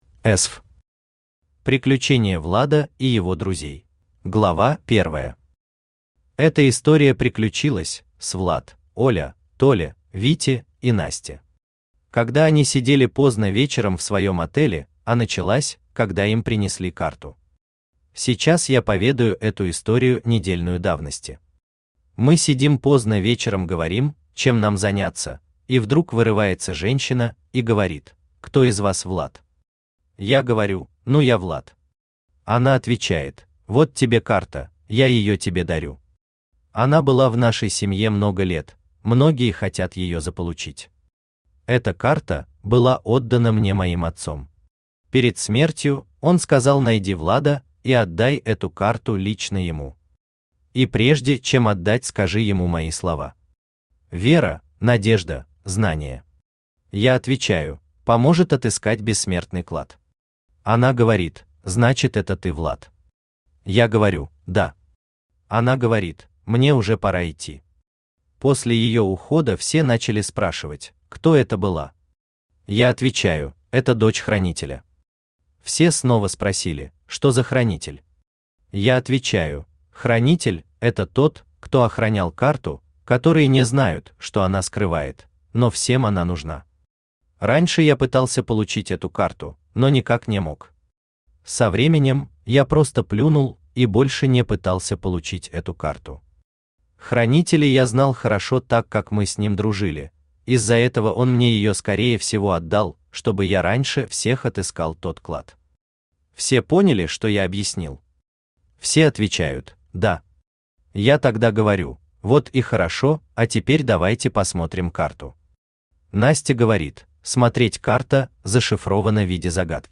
Aудиокнига Приключение Влада и его друзей Автор Эсв Читает аудиокнигу Авточтец ЛитРес.